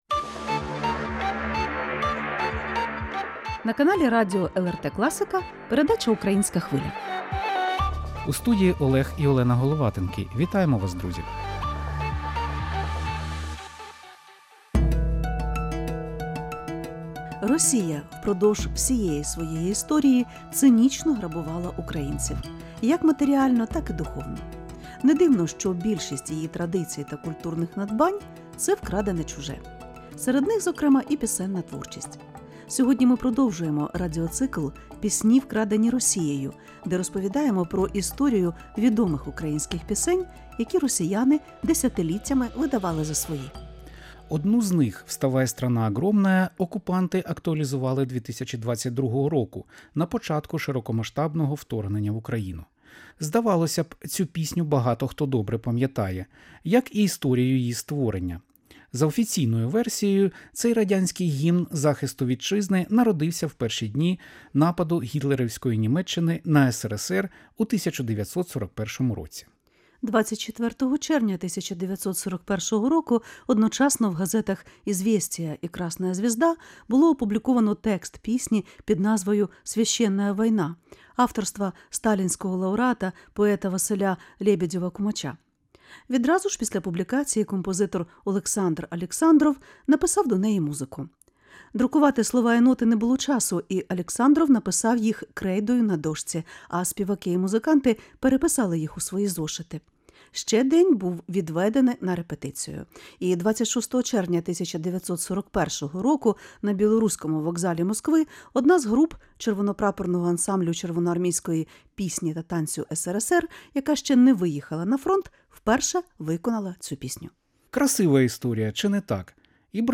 Це розмова про те, як працює культурна окупація: тривала, послідовна, без вибухів, але з переписаними мелодіями.